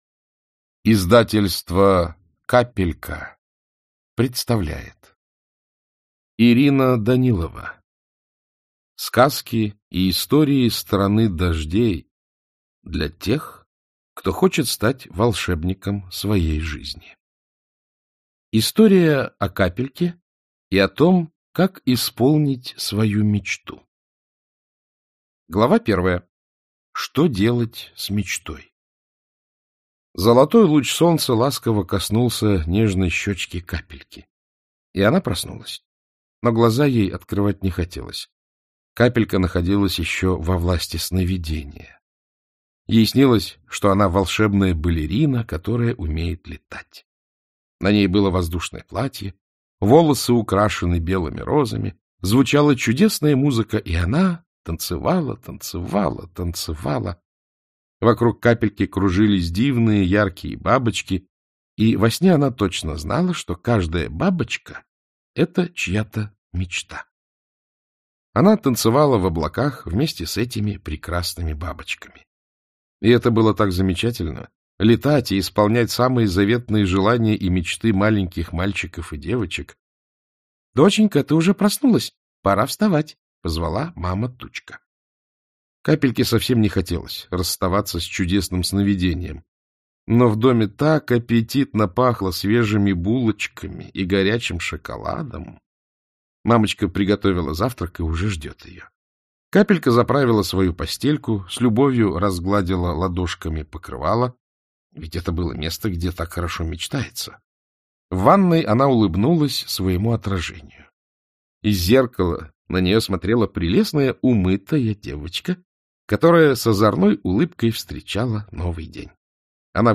Как исполнить свою мечту Автор Ирина Данилова Читает аудиокнигу Александр Клюквин.